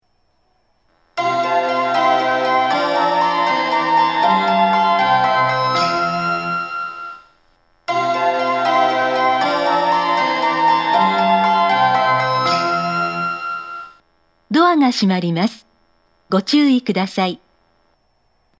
発車メロディーはテイチク製の曲で、音質が非常に良いです。
発車メロディー
発車メロディー 曲が短いので、余韻までは鳴りやすく、車掌次第で2コーラス目も狙えます。